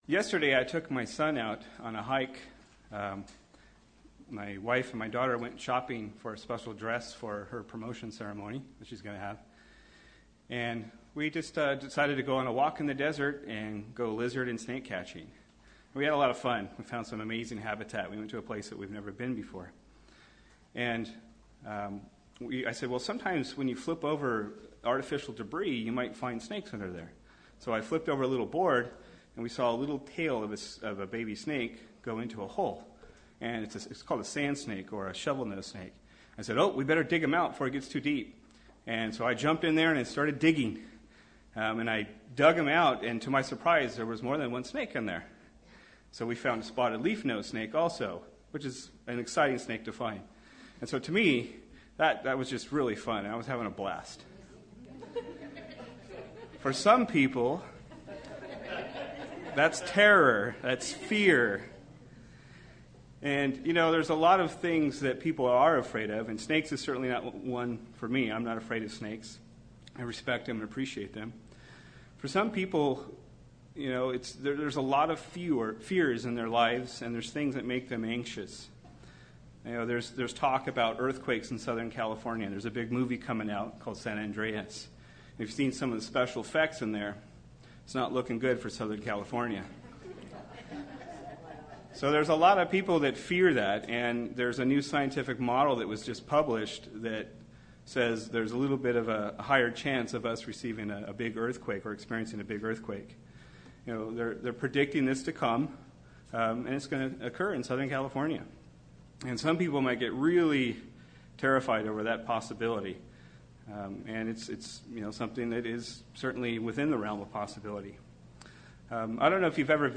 UCG Sermon Studying the bible?
Given in Redlands, CA